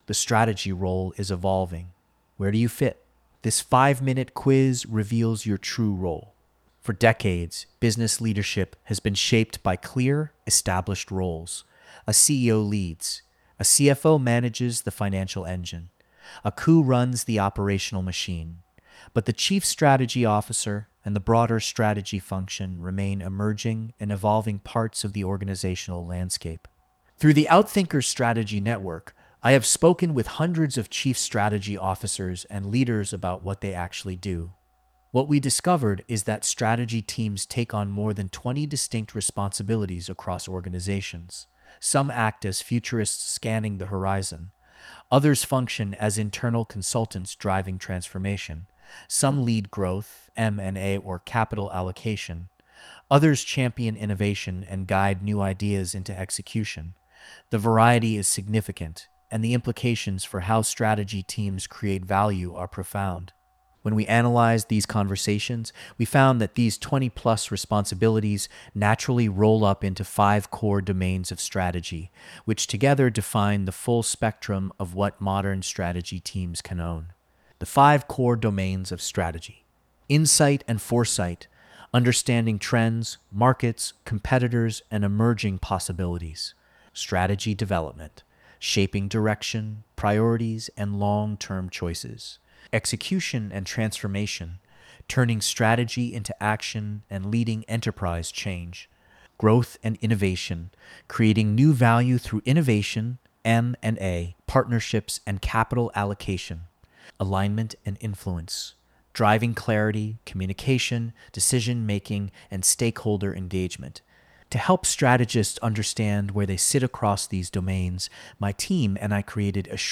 This audio was recorded by AI: